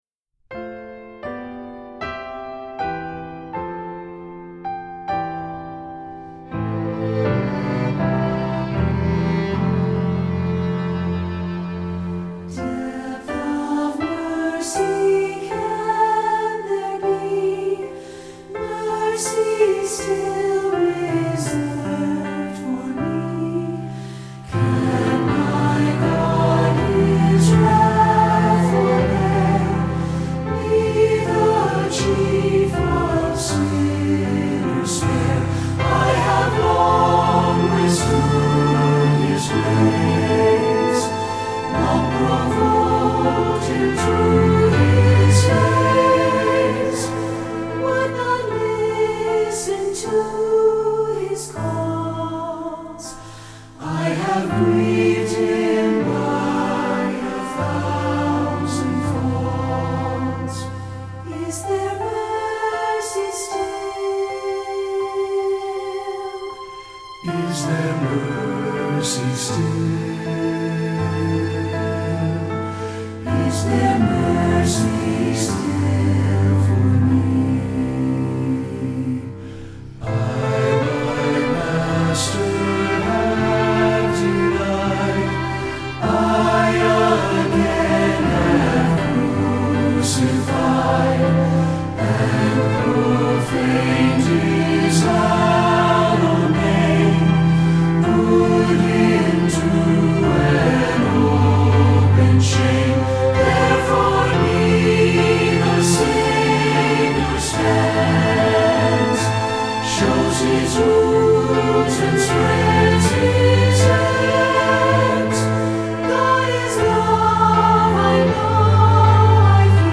Voicing: SATB, Violin and Cello